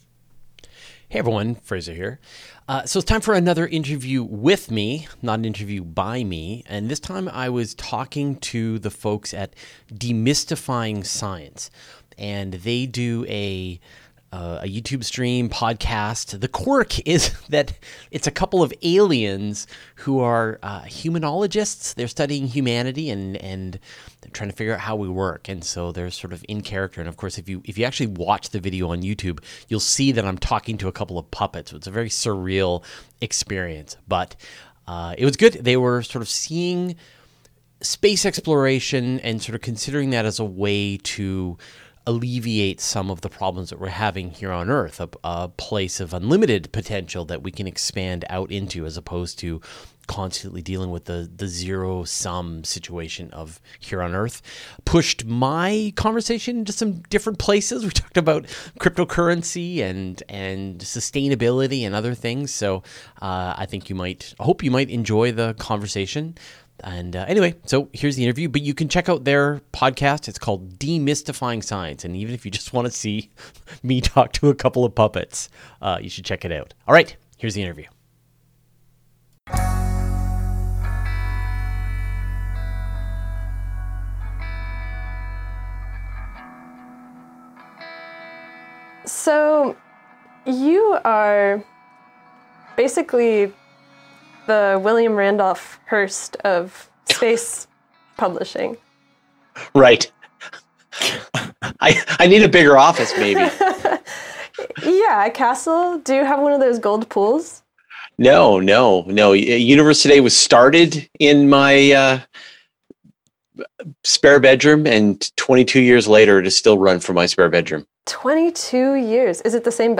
[Q&A] Rogue Planets with Liquid Oceans, Post JWST Telescope Size, Non-EM Space Communication